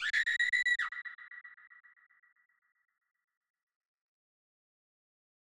TRAVIS SCOTT YELL.wav